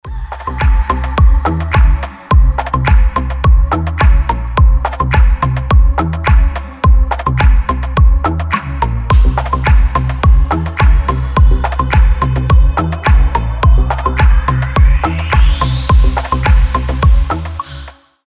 ритмичные
deep house
мелодичные
без слов
звонкие
Чёткий ритмичный музон